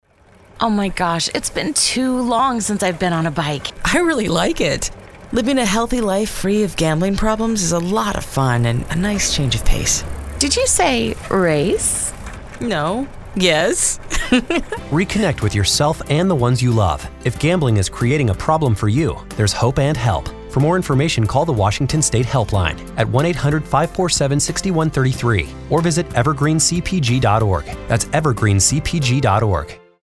Radio Spot: